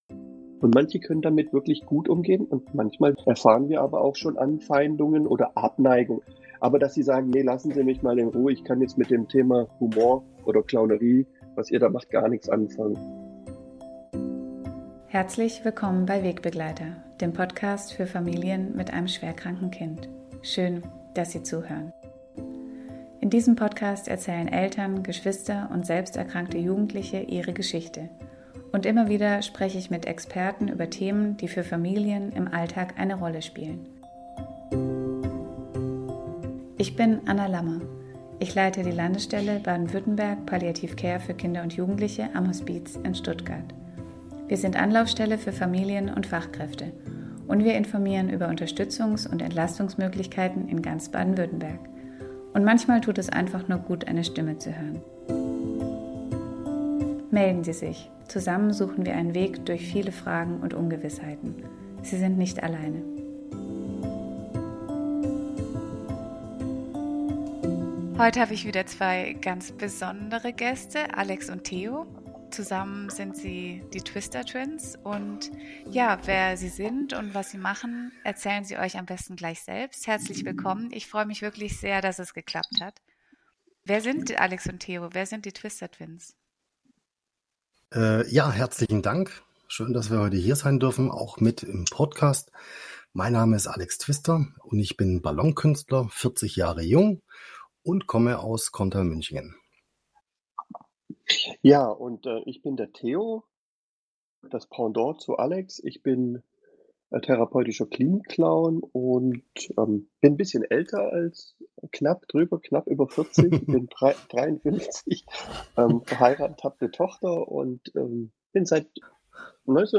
31 | Nimm deine Arbeit ernst, aber nicht zu wichtig. Ein Ballonkünstler und ein Klinikclown erzählen aus ihrem Alltag.
Achtung! In diesem Beitrag wird gelacht 🙂 Und gleichzeitig ist das Gespräch voller Tiefe und Lebensweisheit.